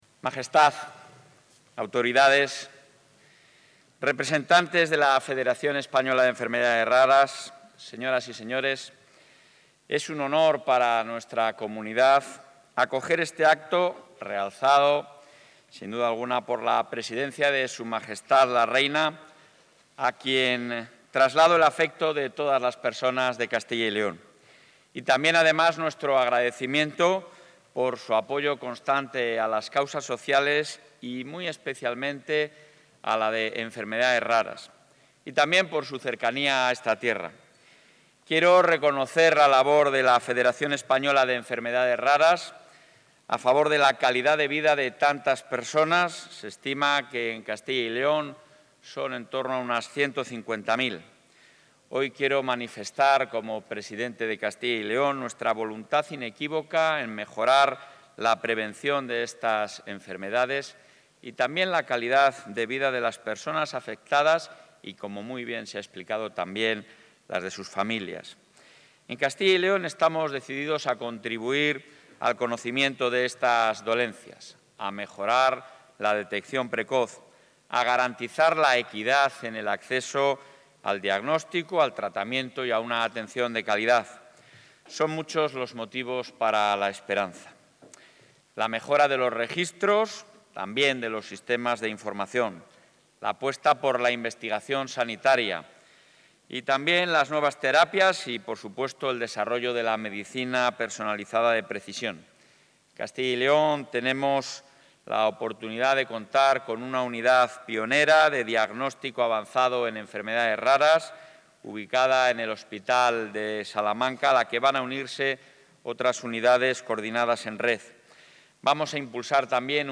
Intervención del presidente de la Junta en funciones.
El presidente de la Junta de Castilla y León en funciones, Alfonso Fernández Mañueco, ha asistido hoy en León al acto central con motivo del Día de las Enfermedades Raras, organizado por la Federación Española de Enfermedades Raras (Feder) bajo la presidencia de Su Majestad la Reina, a quien ha agradecido su apoyo constante a las causas sociales, así como su cercanía a esta tierra.